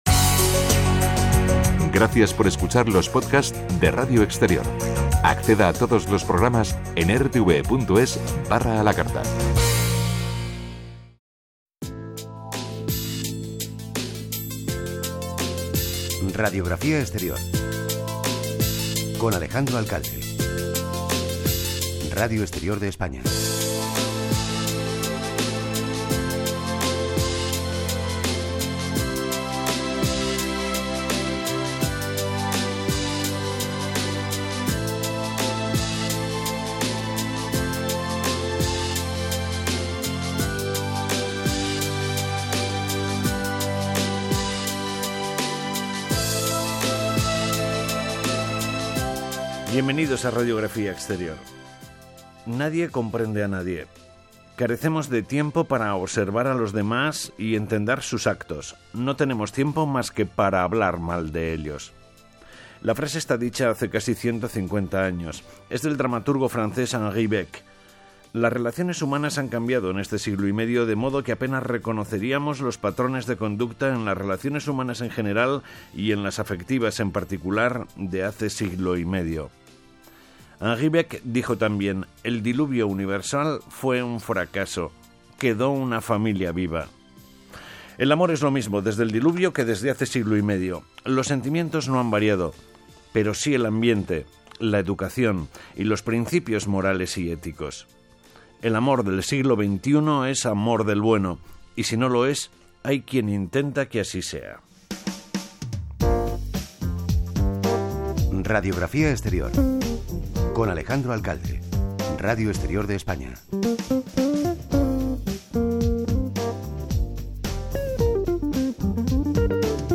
Una delicia de entrevista.
En este podcast la grabación es completa con mi intervención.